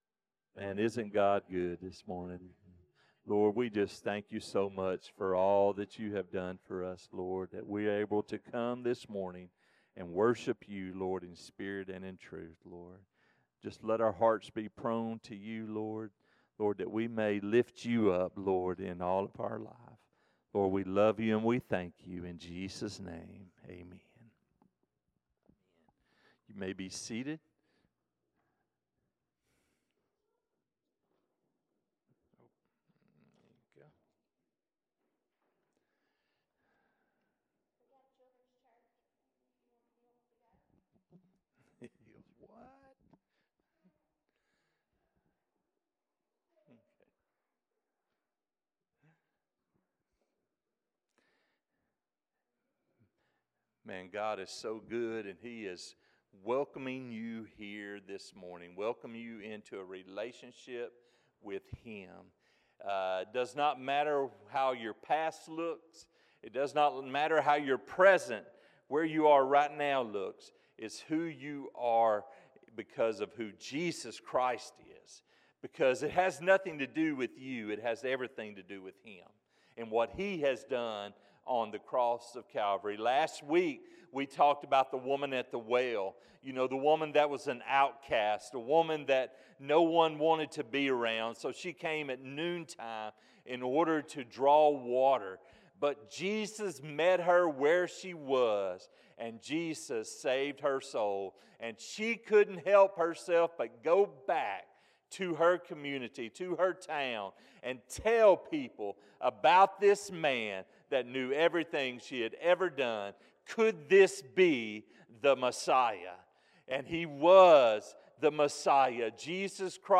Sermons | First Southern Baptist Church Bearden